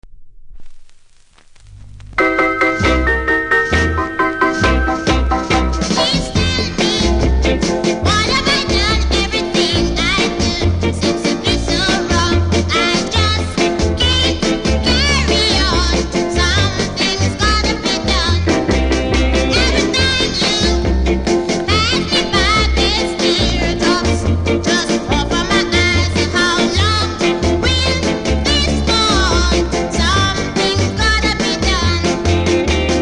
キズ多めで盤の見た目悪いですがノイズはキズほど感じないので試聴で確認下さい。